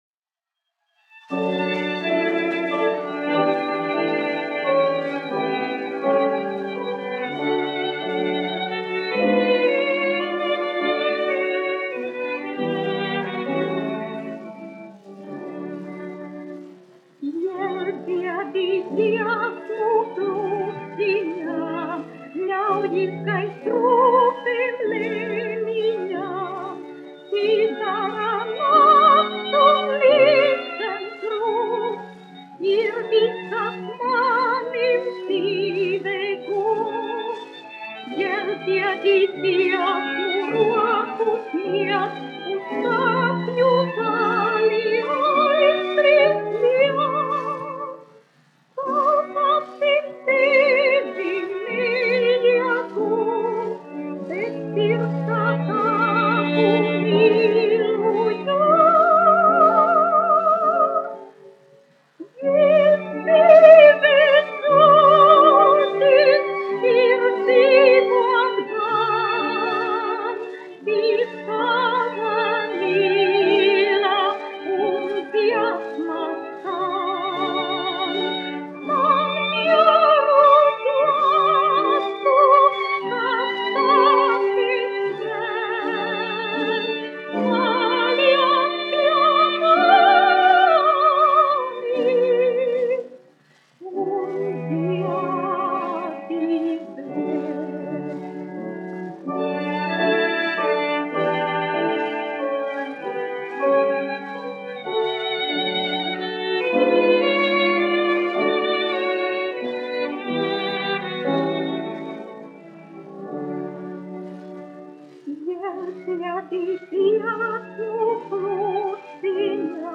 1 skpl. : analogs, 78 apgr/min, mono ; 25 cm
Dziesmas (augsta balss)
Skaņuplate
Latvijas vēsturiskie šellaka skaņuplašu ieraksti (Kolekcija)